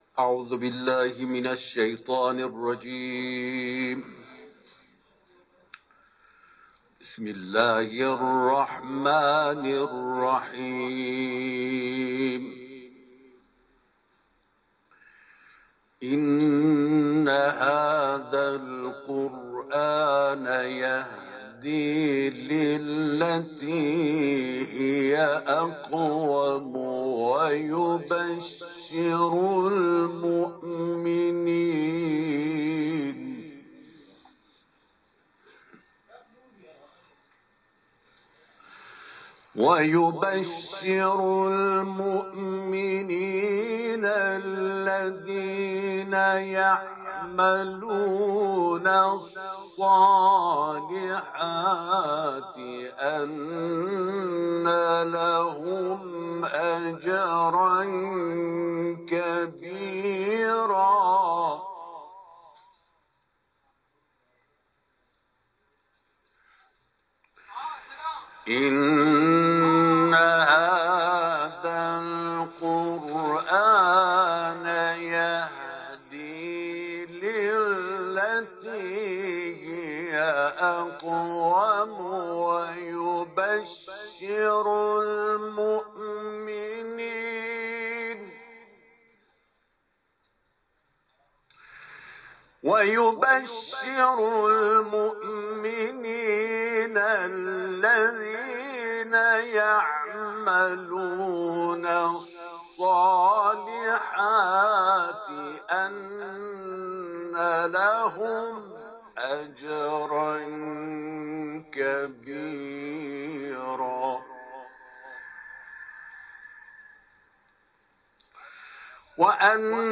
صوت تلاوت
همزمان با اعیاد شعبانیه در حرم مطهر رضوی آیات 9 تا 14 سوره «اسراء» و همچنین سوره «کوثر» را تلاوت کرد.